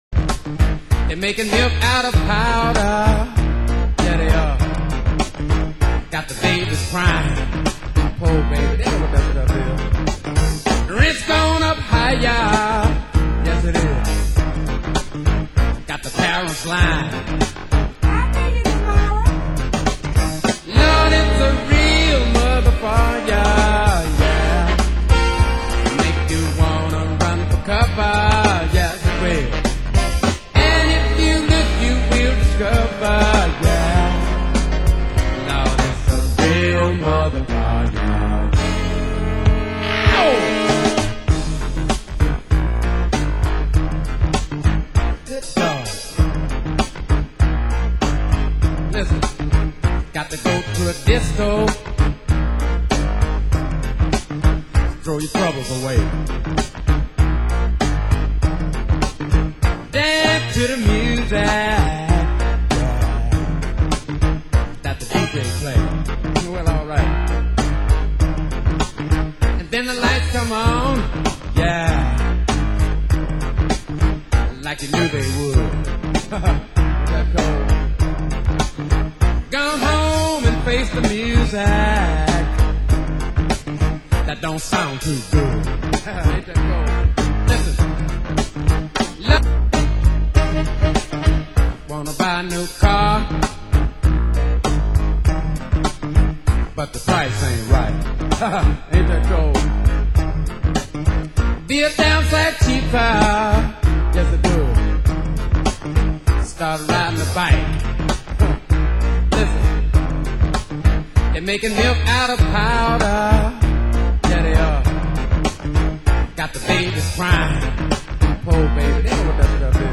Genre: Soul & Funk